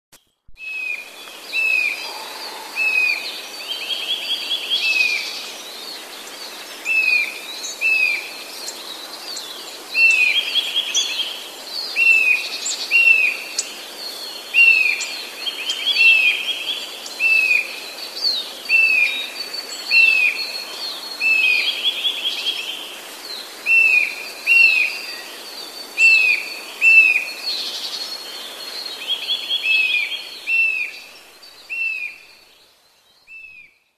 Kowalik - Sitta europaeus
Śpiew to wielokrotnie powtarzane
fletowe gwizdy o sylabach zawiniętych w górę lub w dół.